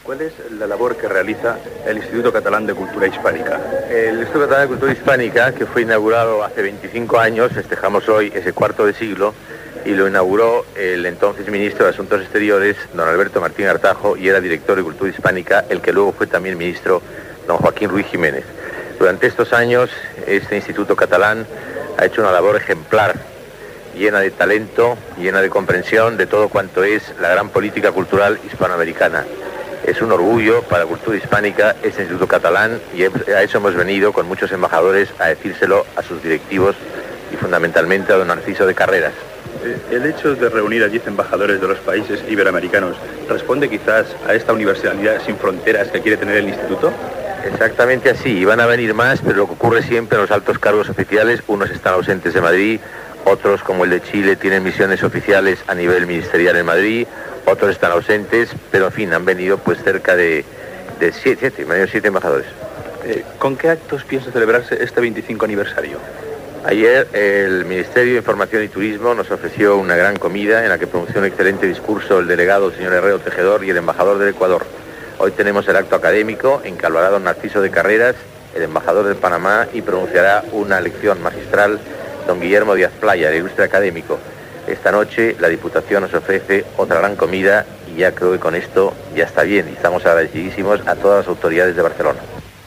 Entrevista
Informatiu